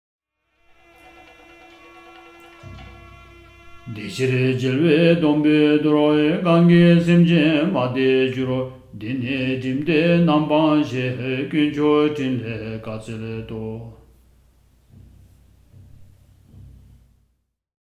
SSC_Verse_Recording_Verse_12_with_music.mp3